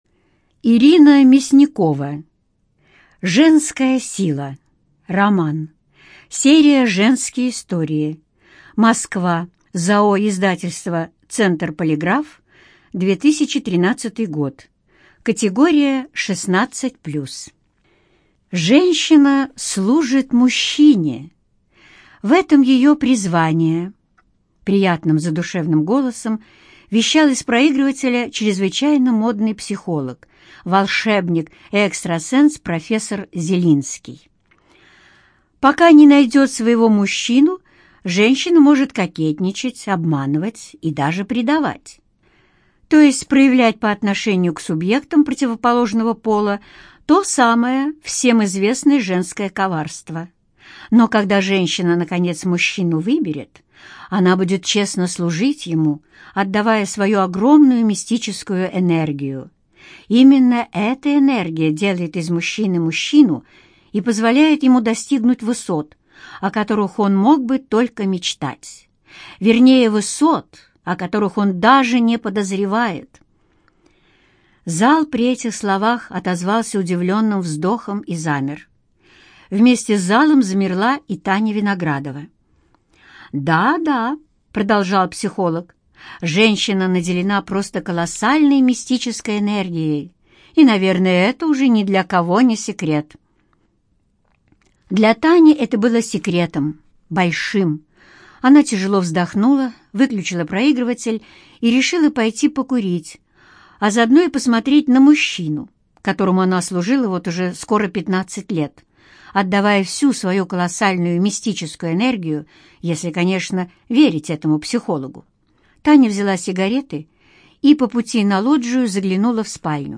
ЖанрЛюбовная проза
Студия звукозаписиЛогосвос